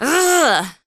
Angry female 2
angry-female-2.mp3